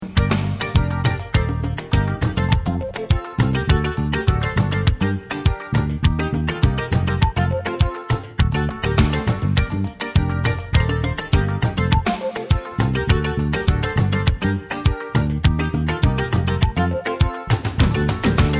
Le style : Zouk Love Antillais aux influences Reggae, Ragga